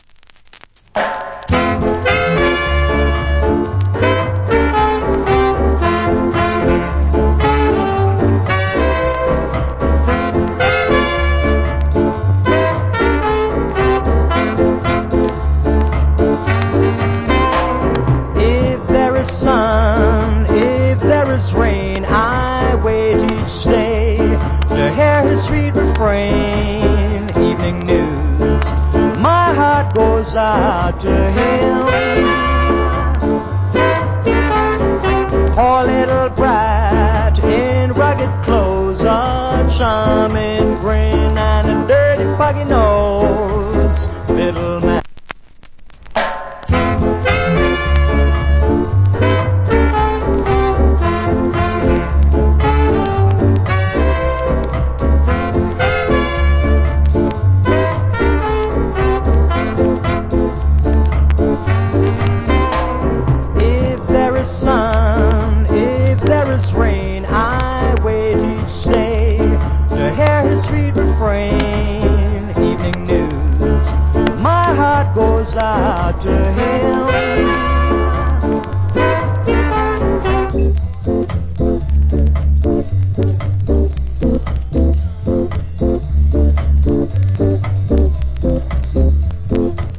Nice Ska Vocal.